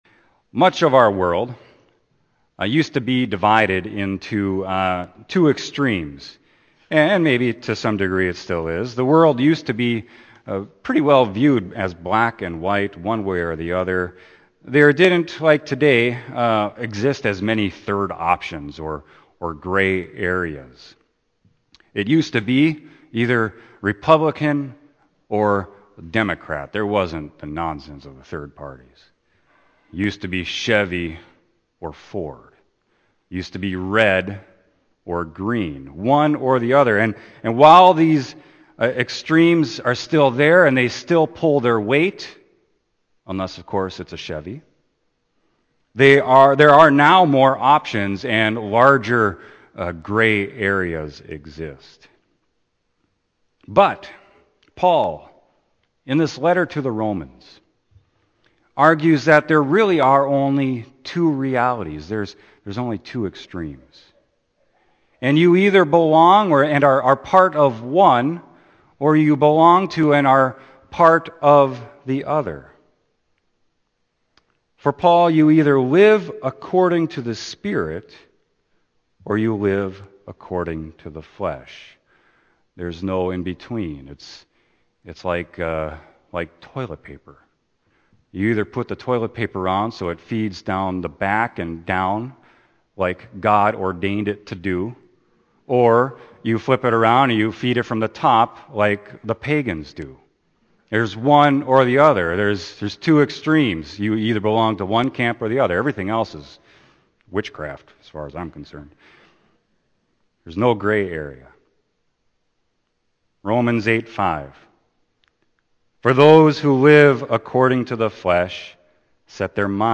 Sermon: Romans 8.1-11